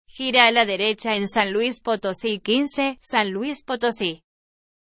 Entidad Federativa Prefijo Ejemplo TTS (Ariane)